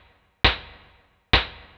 K-7 Clap.wav